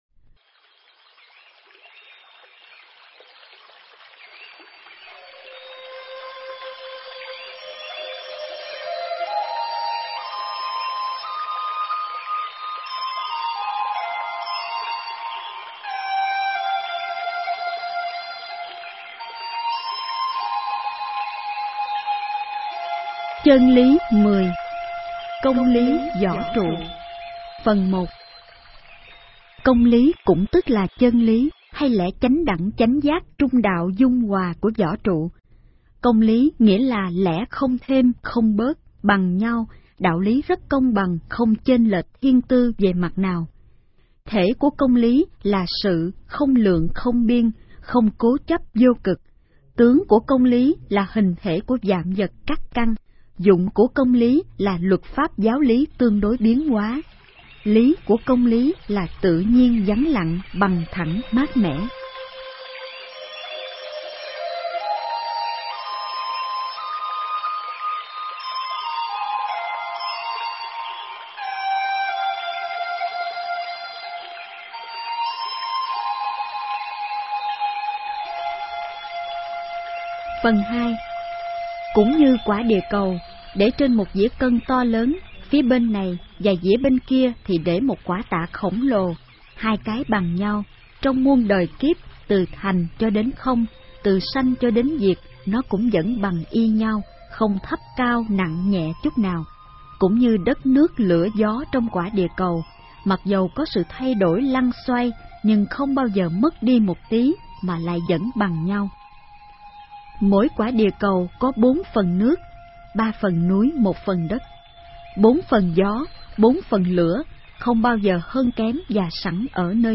Nghe sách nói chương 10.